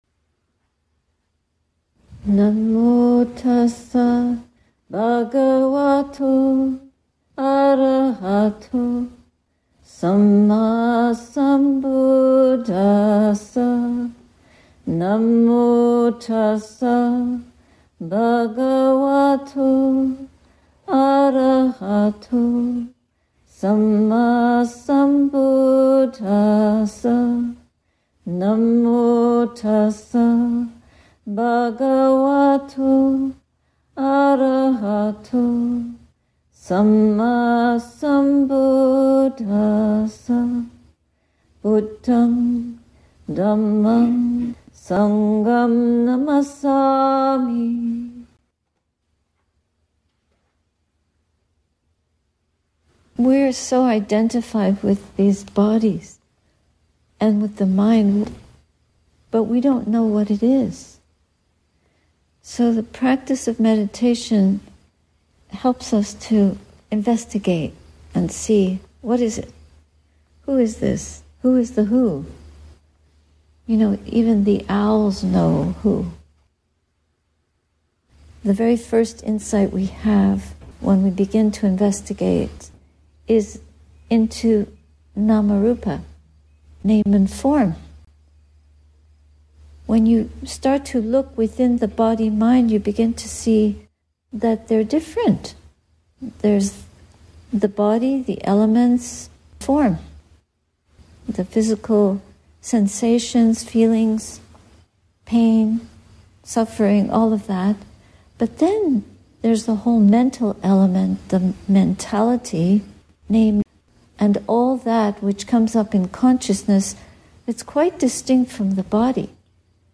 Satipanñā Insight Meditation (SIMT) retreat, Chapin Mill, USA